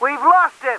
1 channel
VOC_COPS_33_ENGLISH.wav